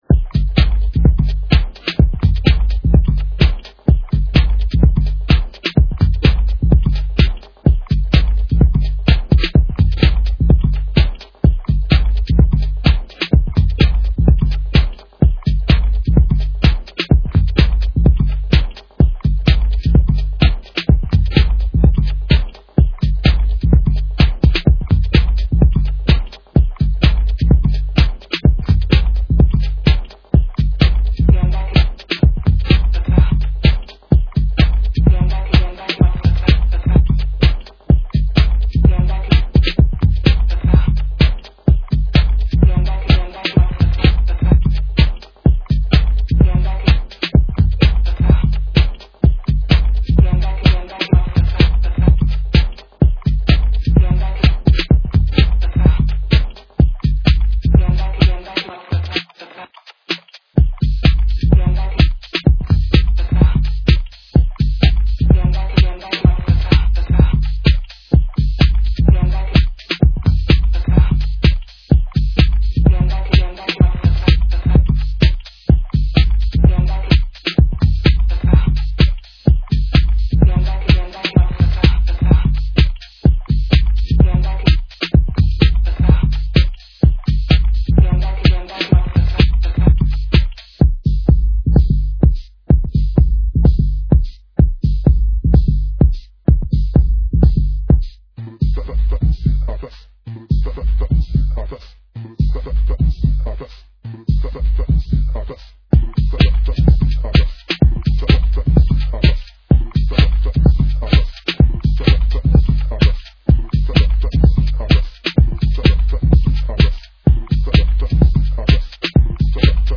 melodic minimalism to techhouse